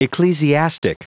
Prononciation du mot ecclesiastic en anglais (fichier audio)
Prononciation du mot : ecclesiastic